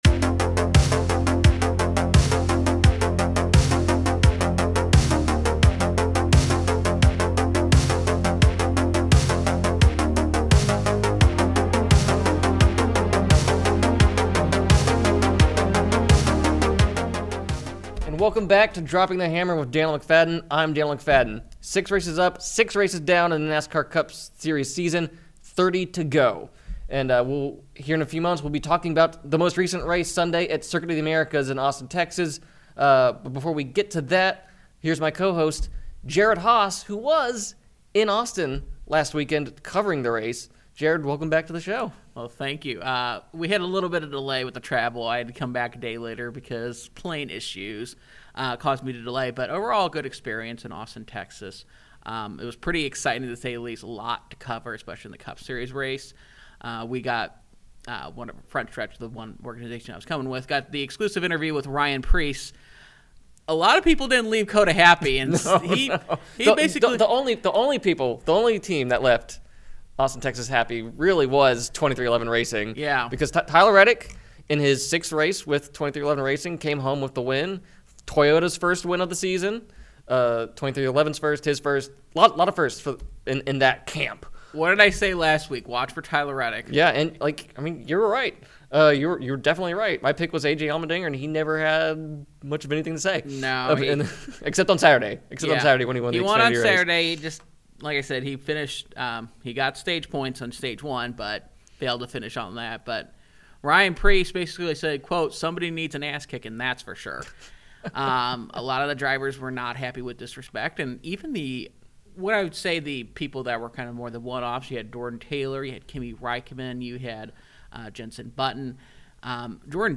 NASCAR to IndyCar: Interview with driver Conor Daly